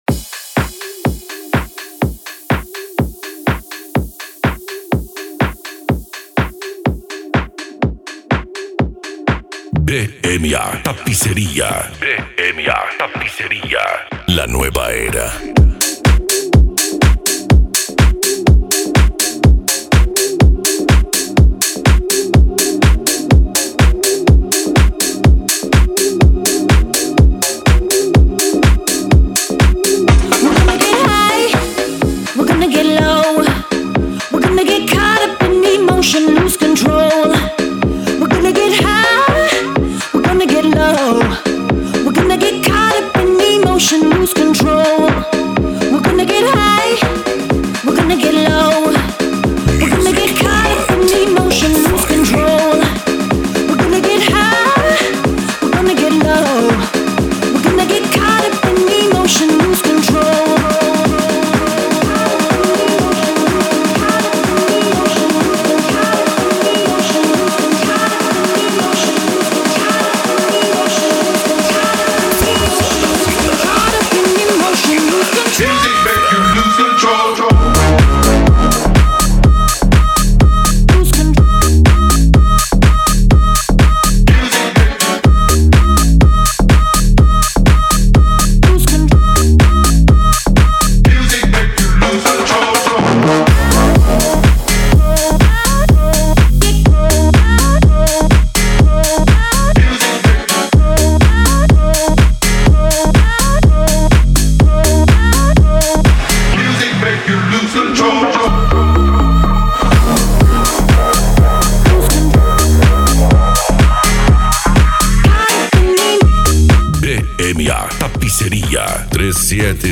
Eletronica
Musica Electronica
Psy Trance